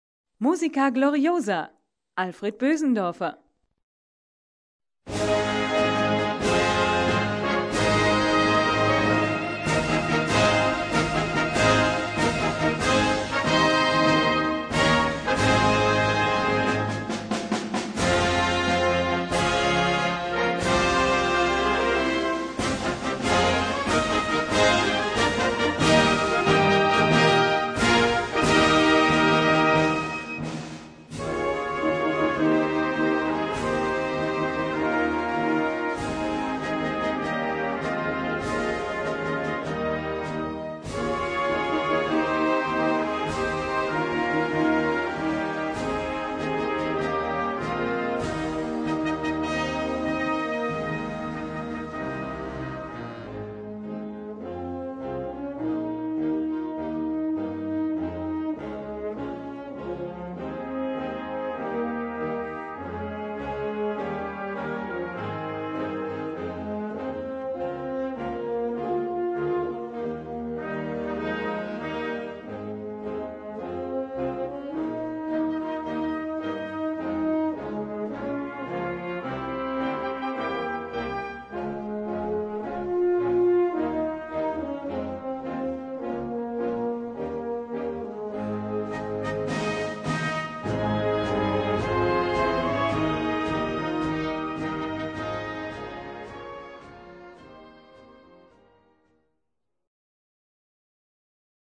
Gattung: Festliche Eröffnungsmusik
Besetzung: Blasorchester